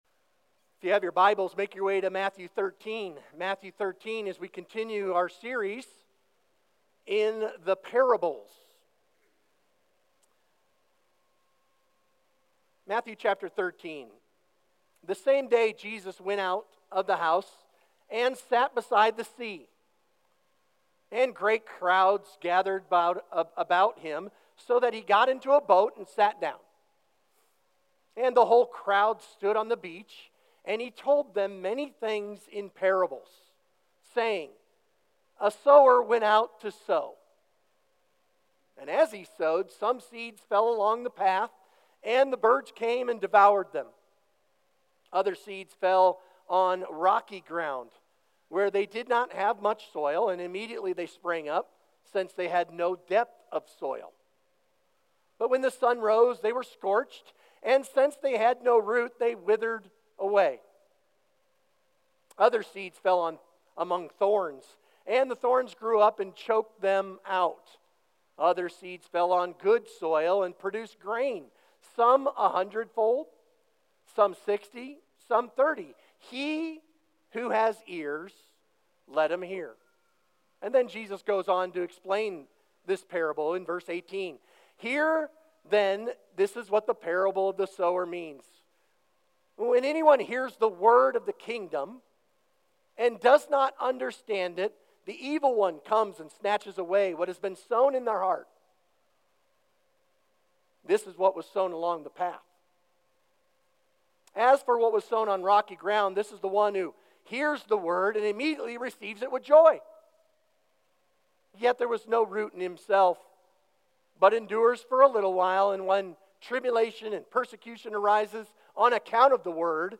Sermon Discussion Read Matthew 13:1–9 and 13:18–23. Key Insight: The sower is faithful. The seed is powerful. The soil makes the difference.